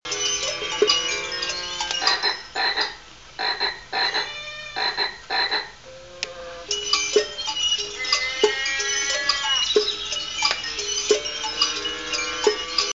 musical sculpture